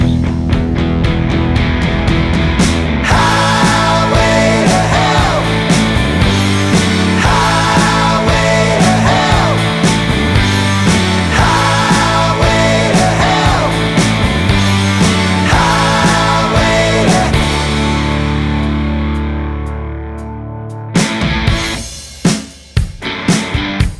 no Backing Vocals Rock 3:26 Buy £1.50